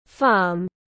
Farm /fɑːm/